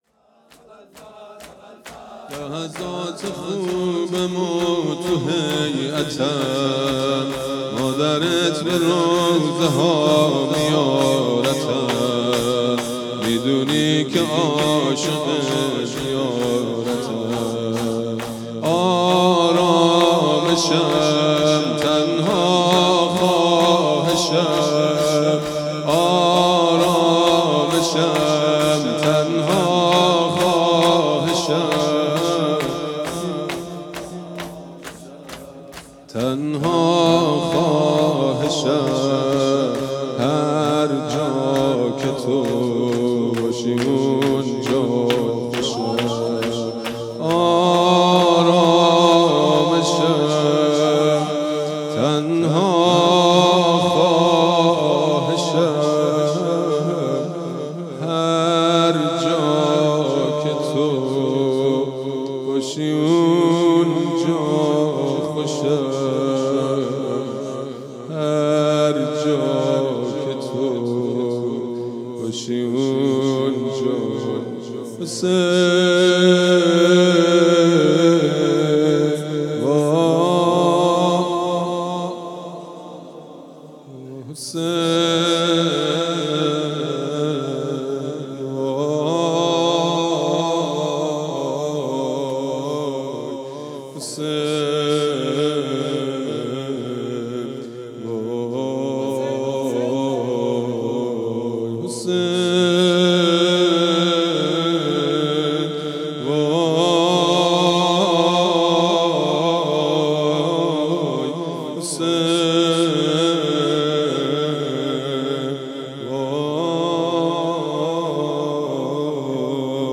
حسینیه بیت النبی